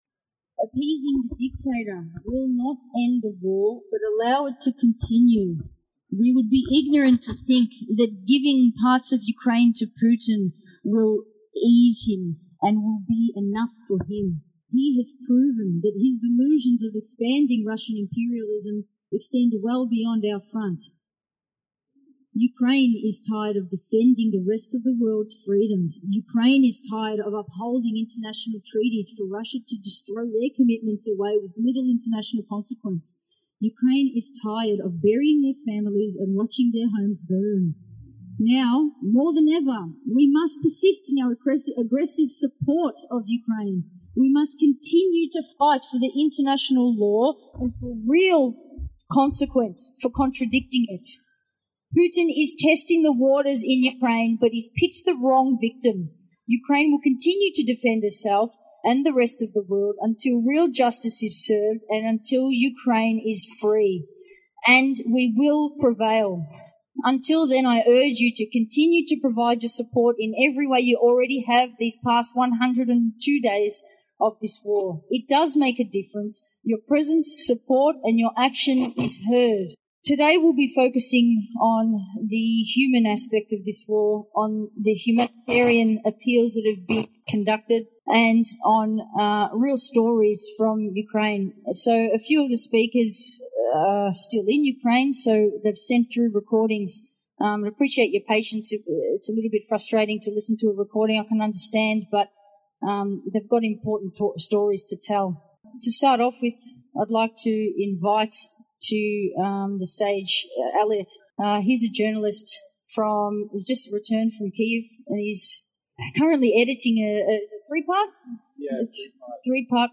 Rally against the war in Ukraine.
Anti-war protest in Melbourne against invasion of Ukraine, 5/06/2022.